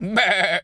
goat_baa_stressed_hurt_09.wav